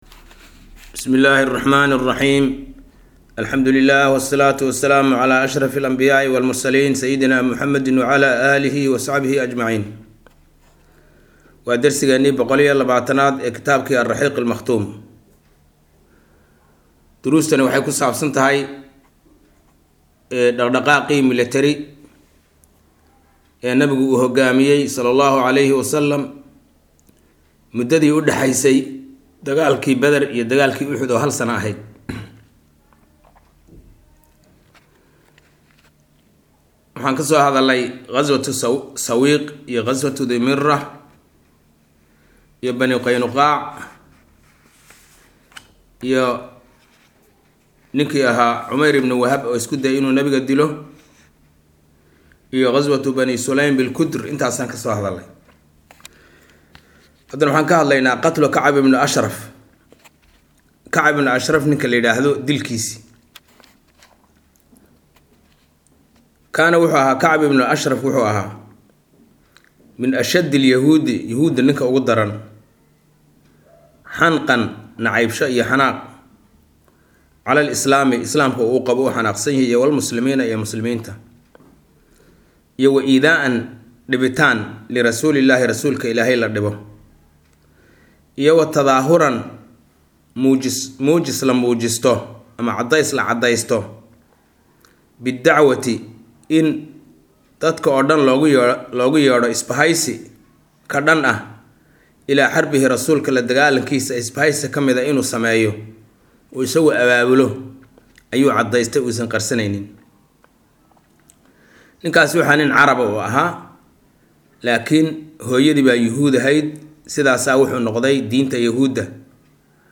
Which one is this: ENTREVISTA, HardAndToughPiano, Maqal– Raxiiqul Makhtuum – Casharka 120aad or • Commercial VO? Maqal– Raxiiqul Makhtuum – Casharka 120aad